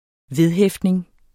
Udtale [ ˈveðˌhεfdneŋ ]